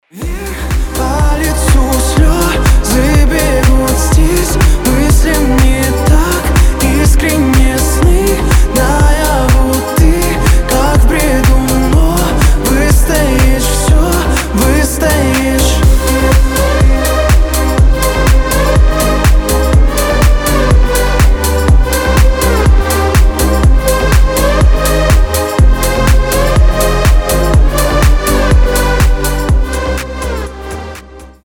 • Качество: 320, Stereo
мужской голос
воодушевляющие